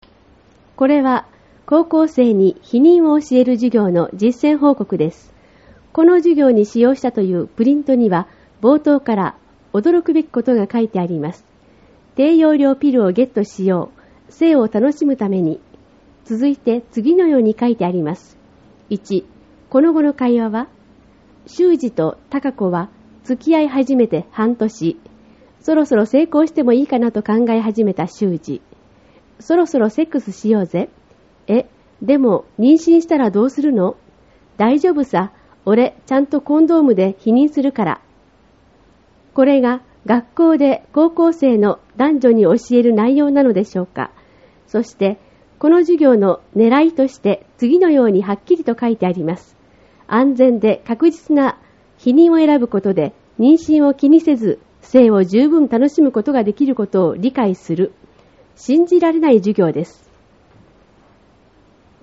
音声による説明 　これは、高校生に「避妊」を教える授業の、実践報告です。